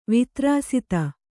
♪ vitrāsita